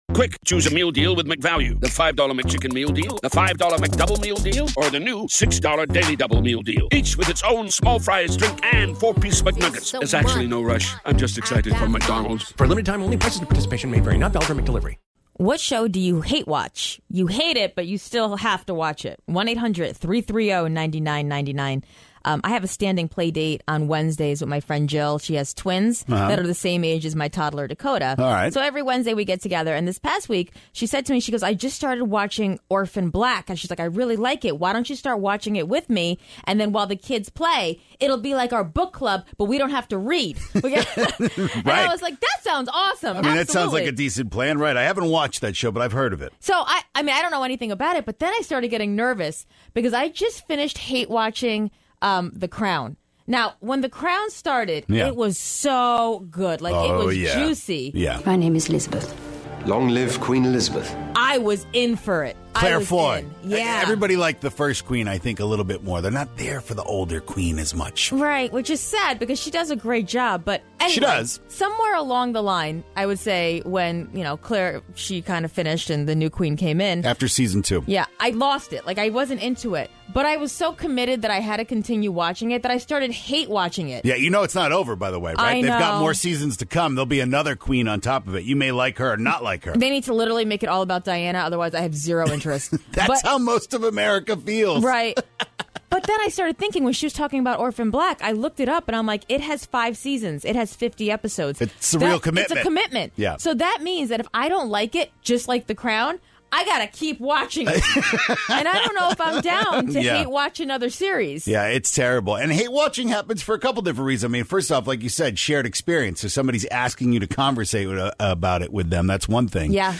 take your calls on the most hate-watchable shows of all time!